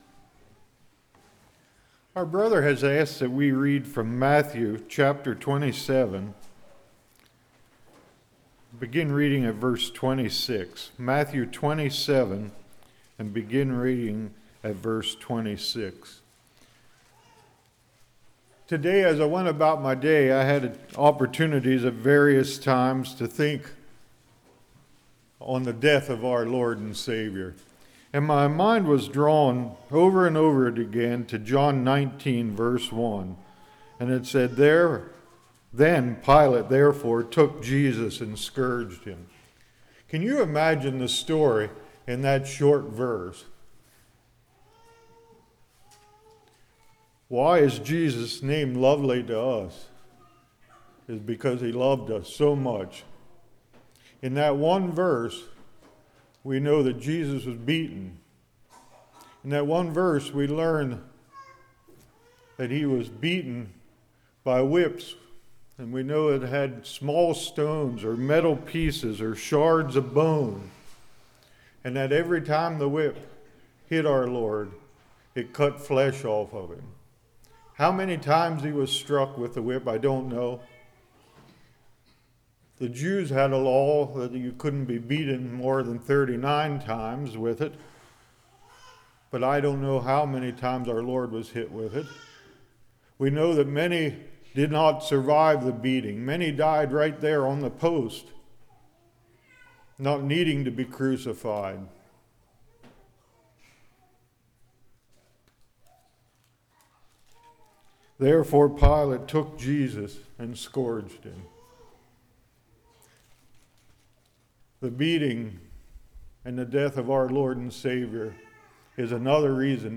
Spring Revival 2018 Passage: Matthew 27:35 Service Type: Revival Cross Death Resurrection What Do I Savour?